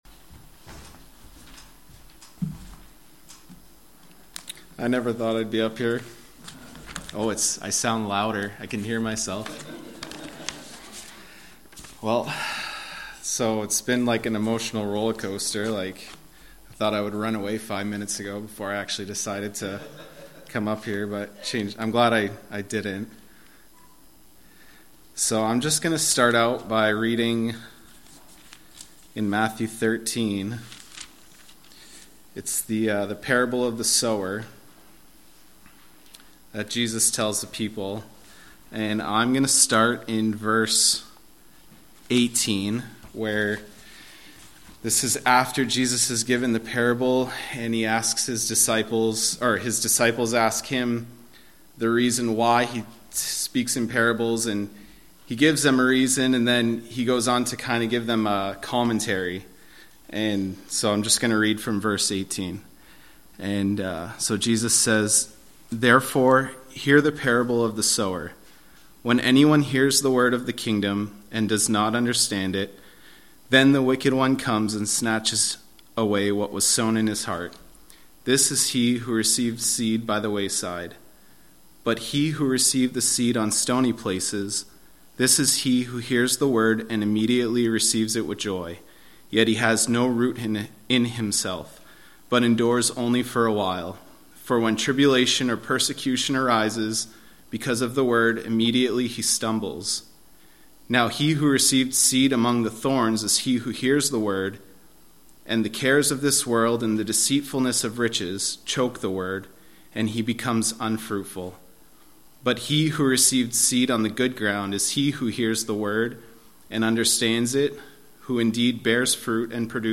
Sermons by Madawaska Gospel Church